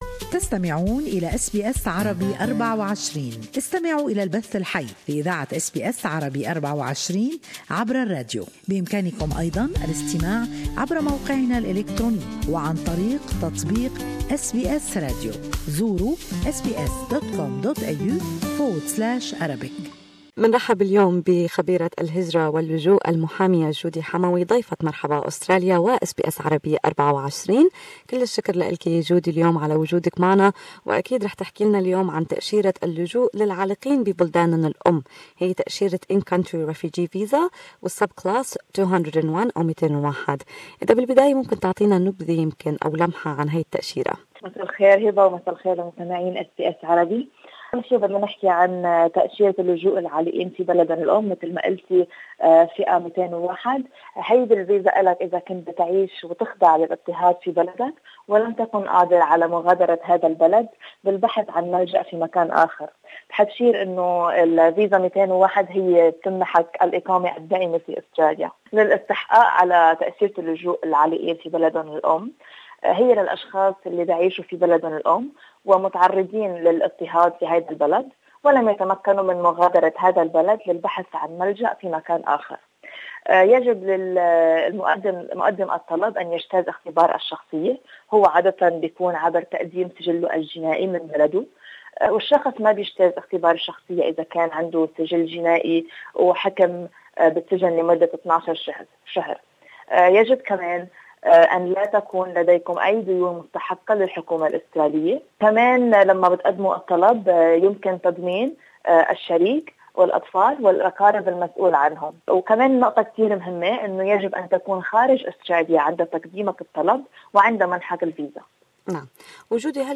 والتفاصيل في المقابلة.